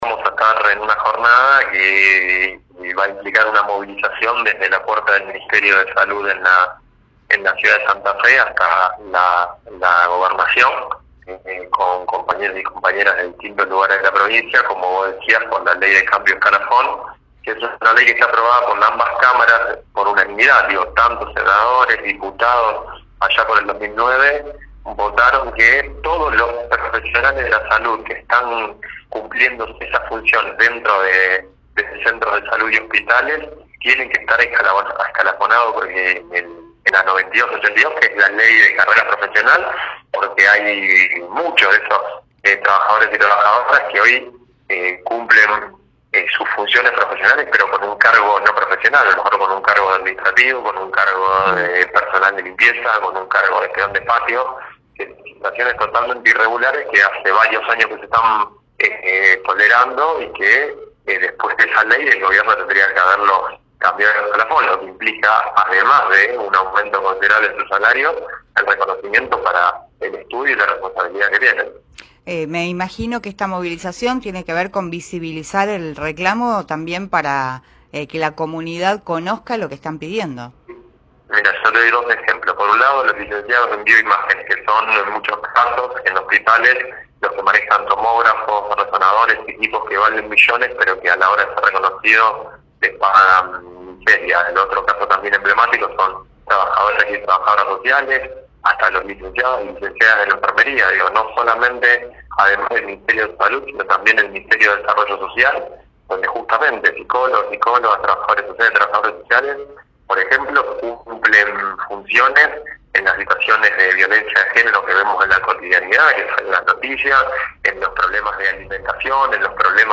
de la Provincia de Santa Fe dialogó con Primera Plana en Cadena 3 Rosario y brindó un panorama sobre esta problemática.